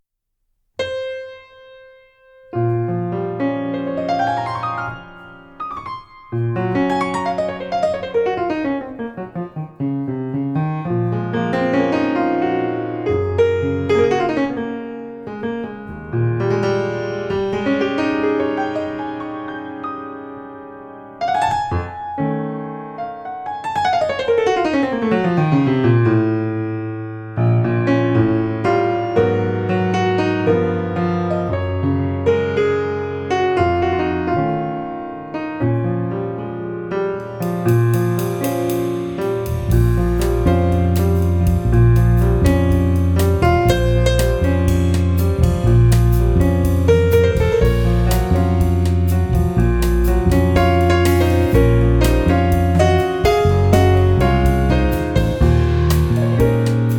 saxes
bass
drums & percussion
pianos and composer